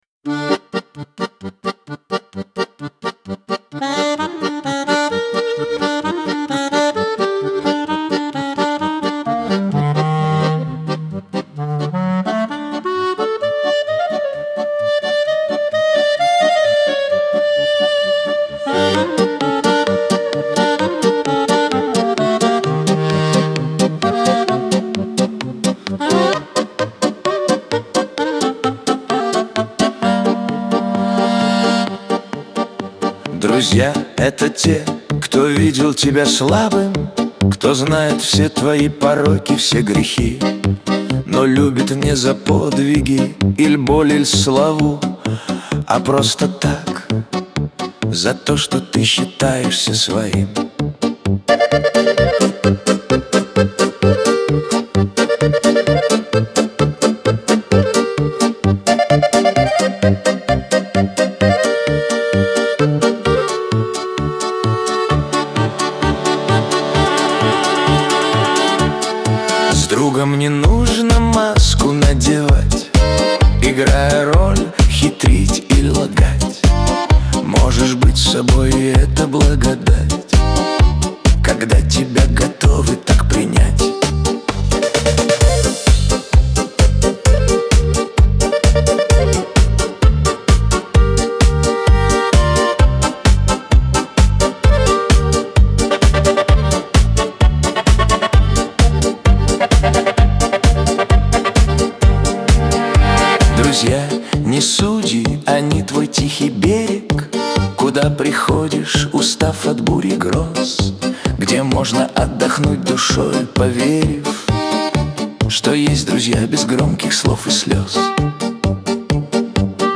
Лирика
pop
Шансон